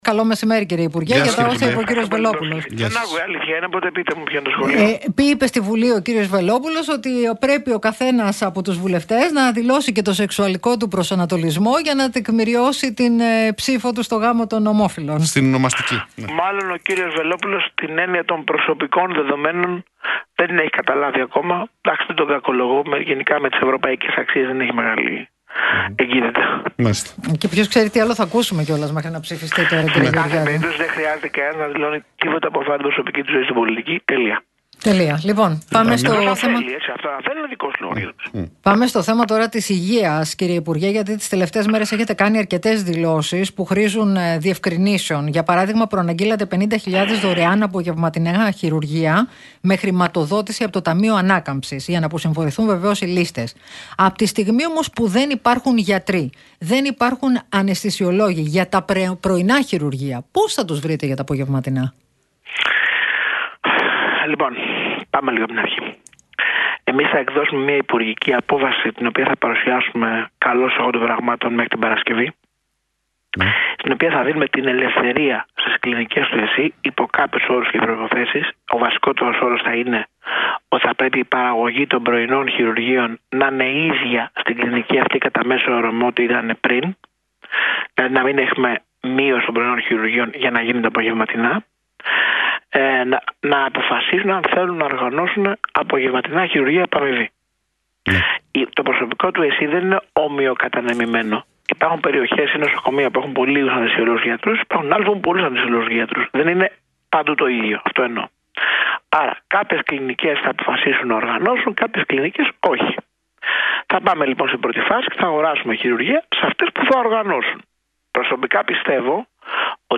Για πλαστά και εικονικά τιμολόγια επί θητείας Παύλου Πολάκη στο υπουργείο Υγείας έκανε λόγο ο υπουργός Υγείας, Άδωνις Γεωργιάδης, μιλώντας στον Realfm 97,8